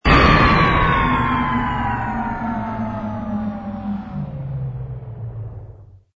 engine_rh_cruise_stop.wav